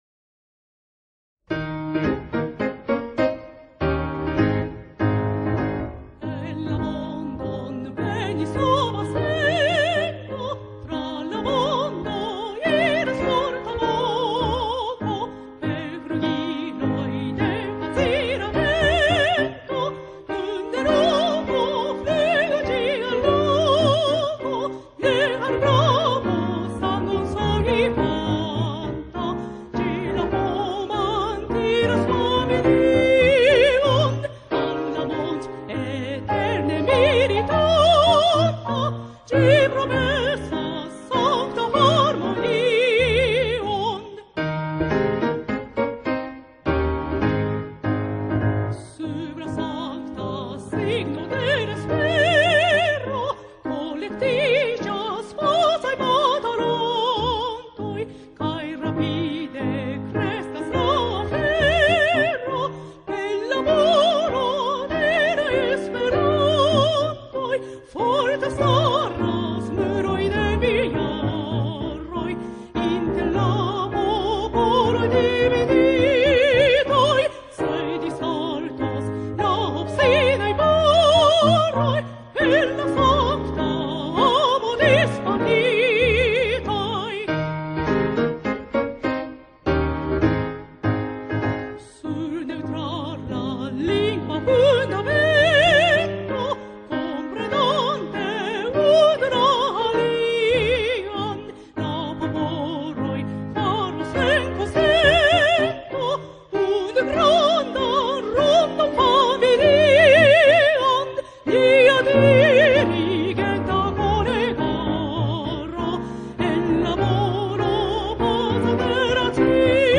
Chanté en espéranto par un choeur / esperante kantita de ĥoro
cantatrice et un choeur.
aŭskultu kantistinon kaj ĥoron.